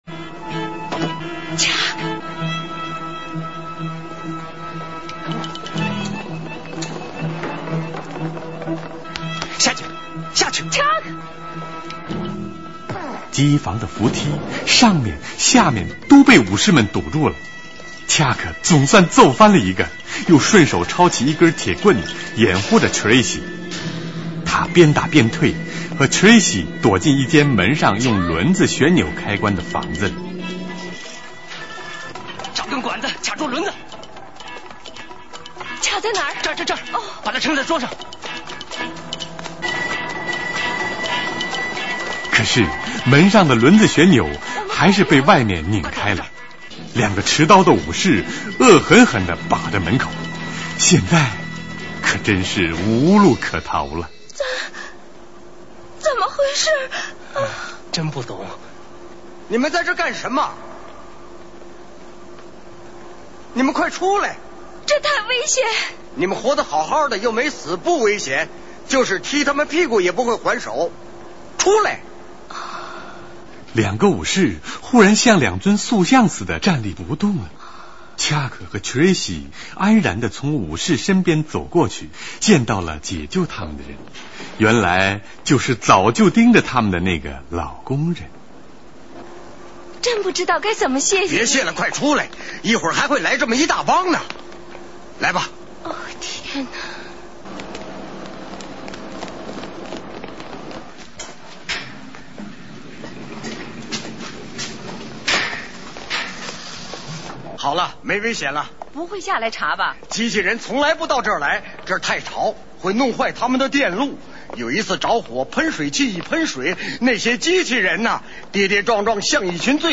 [4/5/2009]美国电影【未来世界】录音剪辑(上海电影译制厂译制）
主要配音演员：
童自荣 李梓 邱岳峰 于鼎 尚华